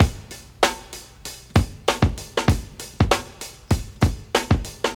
97 Bpm Drum Loop D# Key.wav
Free breakbeat sample - kick tuned to the D# note. Loudest frequency: 1318Hz
.WAV .MP3 .OGG 0:00 / 0:05 Type Wav Duration 0:05 Size 852,42 KB Samplerate 44100 Hz Bitdepth 16 Channels Stereo Free breakbeat sample - kick tuned to the D# note.
97-bpm-drum-loop-d-sharp-key-KJA.ogg